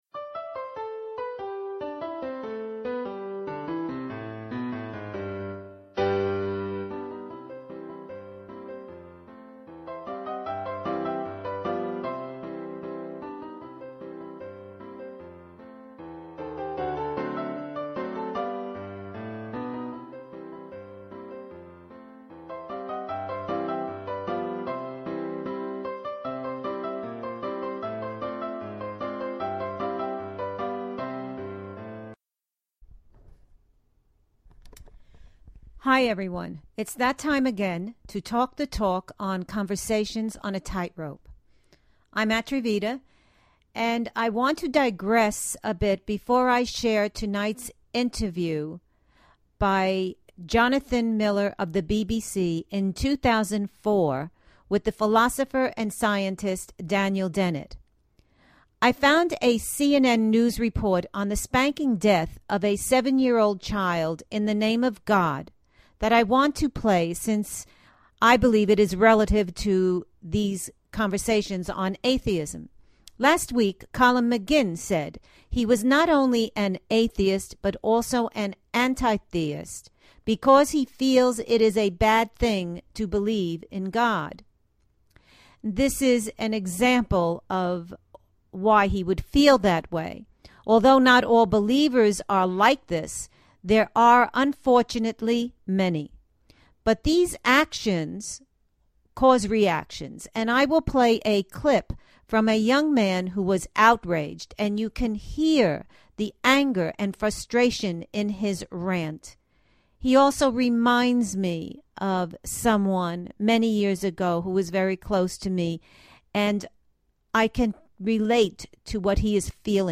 Featuring a 2004 BBC interview with Daniel Dennett.